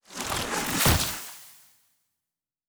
Nature Spell 25.wav